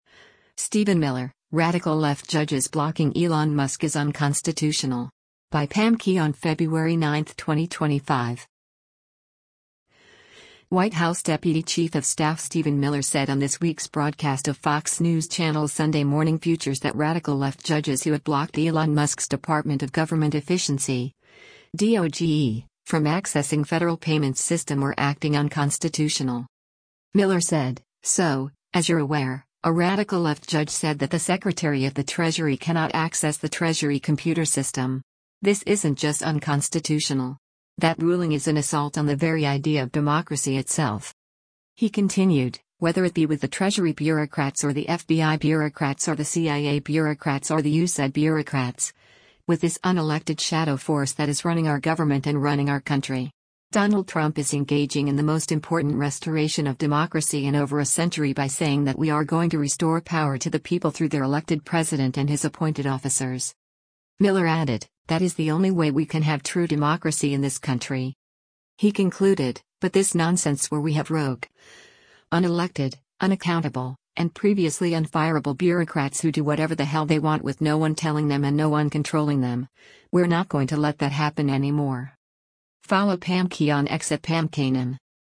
White House deputy chief of staff Stephen Miller said on this week’s broadcast of Fox News Channel’s “Sunday Morning Futures” that “radical left” judges who have blocked Elon Musk’s Department of Government Efficiency (DOGE) from accessing federal payments system were acting “unconstitutional.”